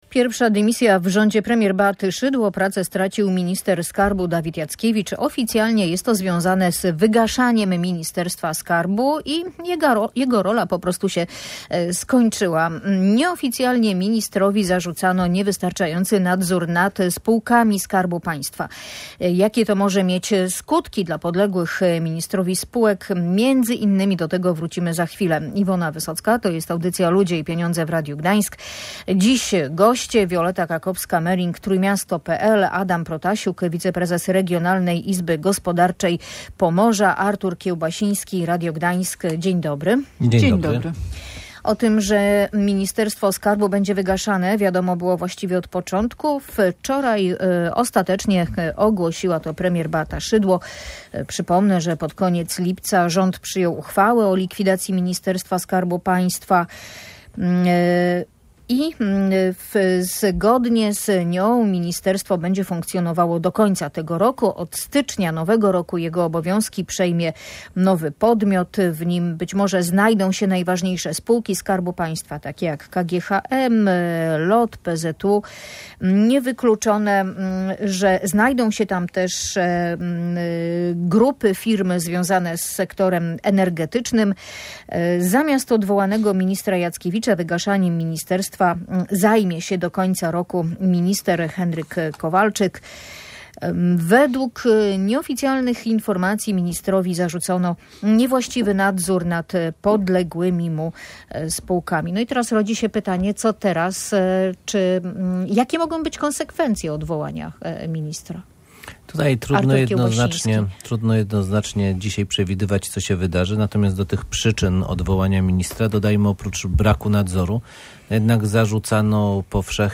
Decyzje UOKiK były tematem audycji Ludzie i Pieniądze.